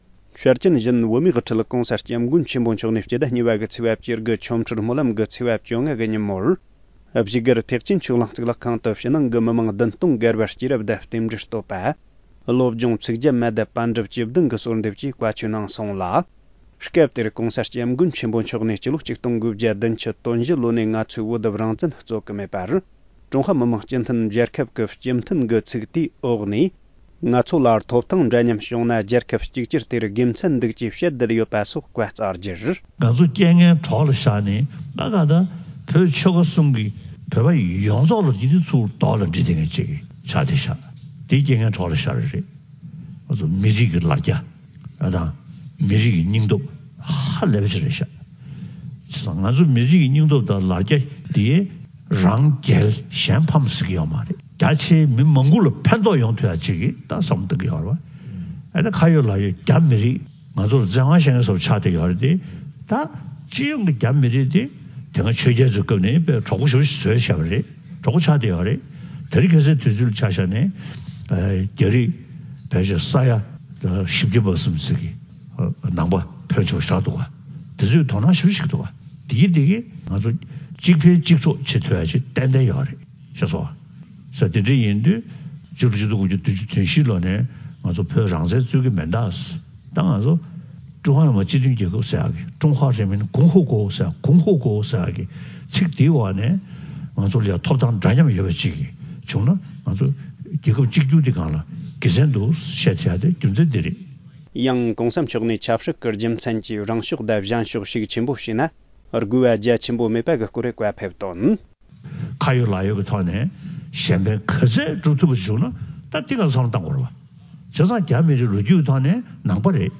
༧གོང་ས་མཆོག་གིས་ཆོ་འཕྲུལ་སྨོན་ལམ་གྱི་གསུངས་ཆོས་སྐབས། ༢༠༡༩།༢།༡༩ ༧གོང་ས་མཆོག་གིས་ཆོ་འཕྲུལ་སྨོན་ལམ་གྱི་གསུངས་ཆོས་སྐབས། ༢༠༡༩།༢།༡༩
སྒྲ་ལྡན་གསར་འགྱུར། སྒྲ་ཕབ་ལེན།